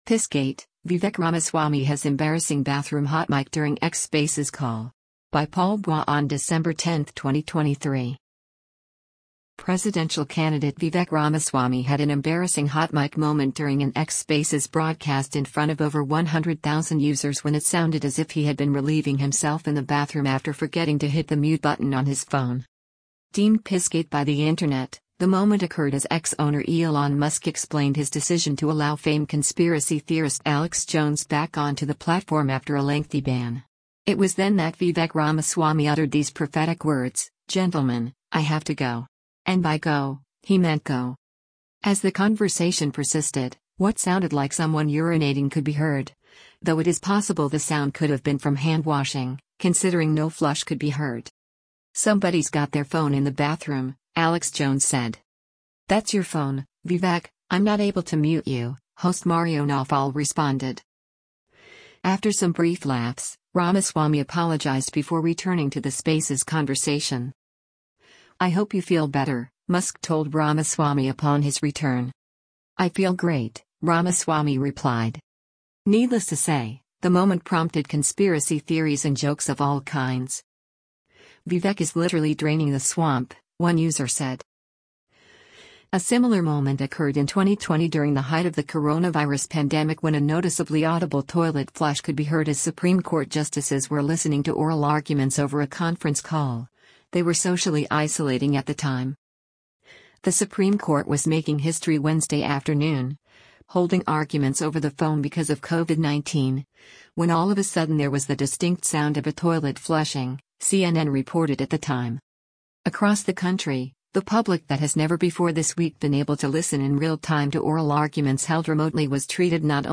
Presidential candidate Vivek Ramaswamy had an embarrassing hot mic moment during an X Spaces broadcast in front of over 100,000 users when it sounded as if he had been relieving himself in the bathroom after forgetting to hit the mute button on his phone.
As the conversation persisted, what sounded like someone urinating could be heard, though it is possible the sound could have been from hand-washing, considering no flush could be heard.
After some brief laughs, Ramaswamy apologized before returning to the Spaces conversation.